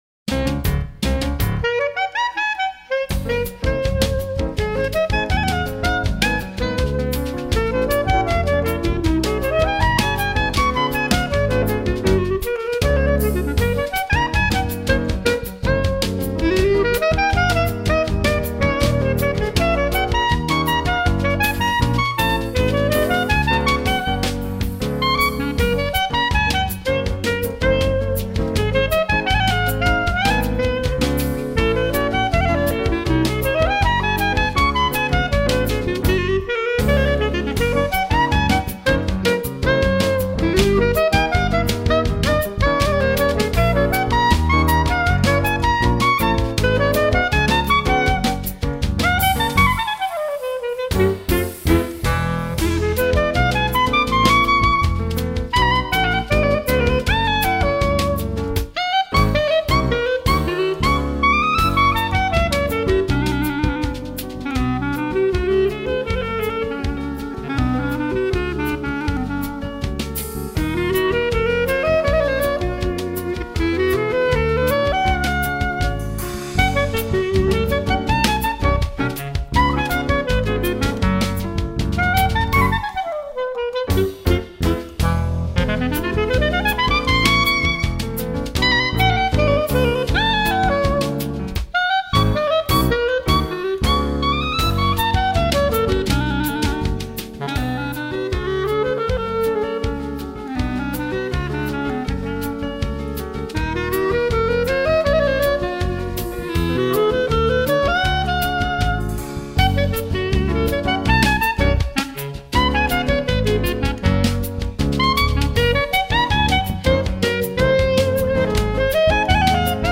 2024   02:26:00   Faixa:     Instrumental